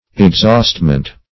Exhaustment \Ex*haust"ment\, n.